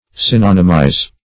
synonymize - definition of synonymize - synonyms, pronunciation, spelling from Free Dictionary
Synonymize \Syn*on"y*mize\, v. t. [imp. & p. p. Synonymized;